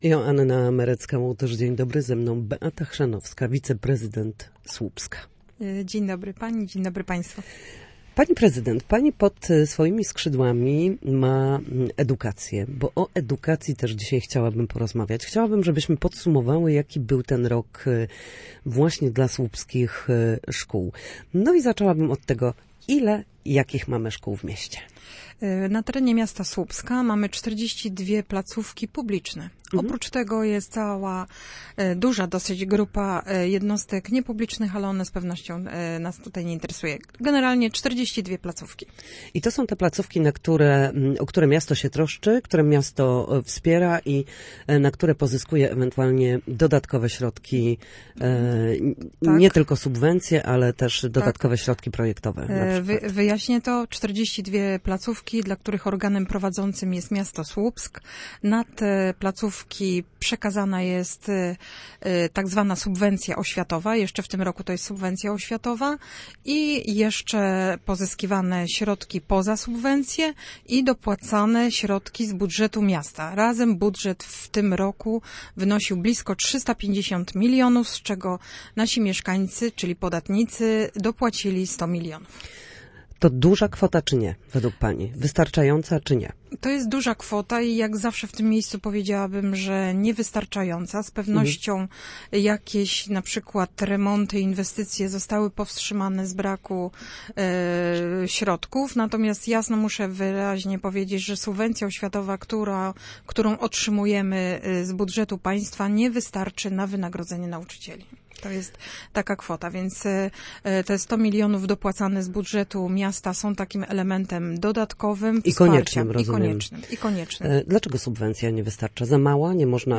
Gościem Studia Słupsk był dziś Beata Chrzanowska wiceprezydent Słupska. Na naszej antenie podsumowała mijający rok w słupskiej edukacji.